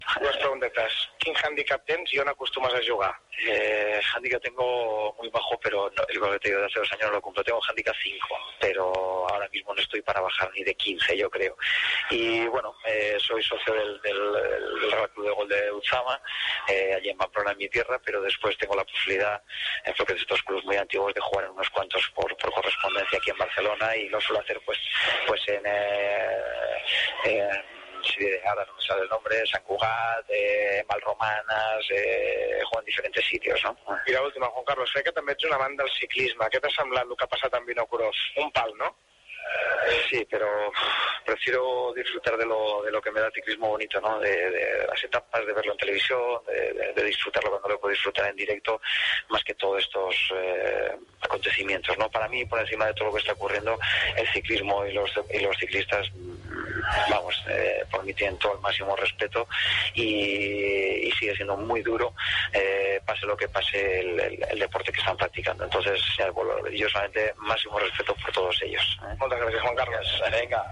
Entrevista al porter del F.C. Barcelona, Juan Carlos Unzué, sobre golf i ciclisme.
Esportiu